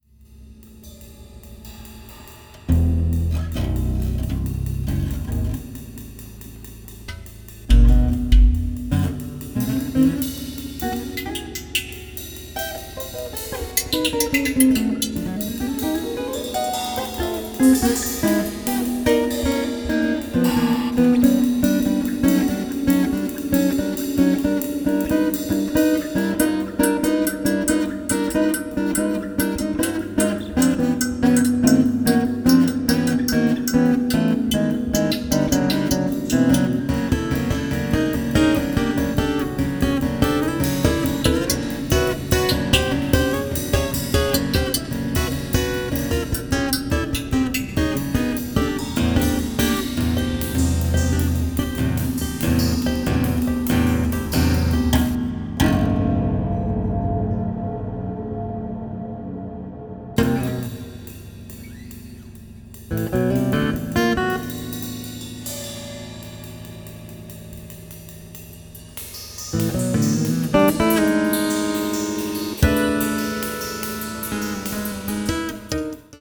30-string Contra-Alto guitar
Percussion